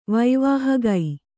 ♪ vaivāhagai